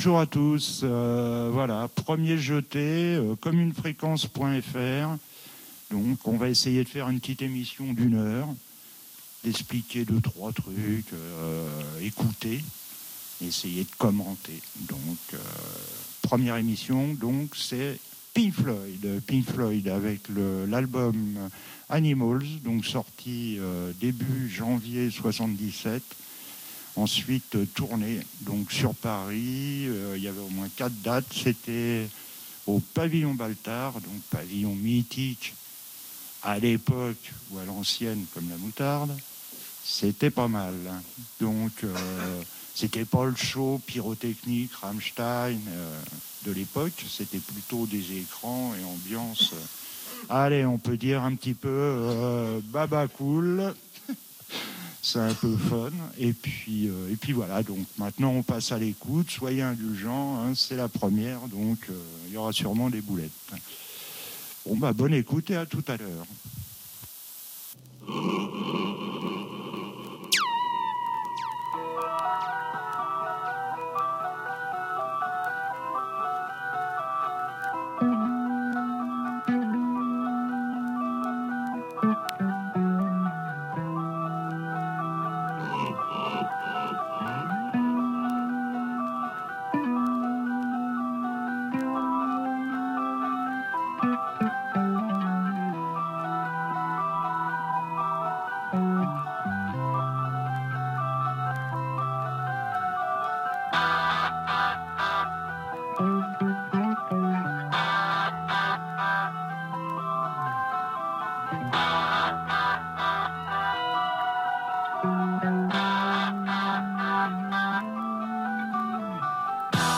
Vieilleuries variétés 70 / 90